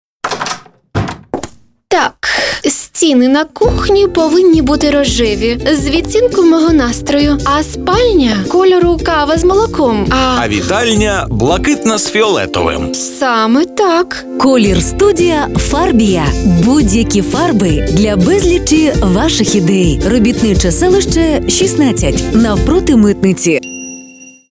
Игровой аудиоролик (украинский язык)
Создание аудиоролика под ключ: сценарий+запись дикторов+монтаж+мастеринг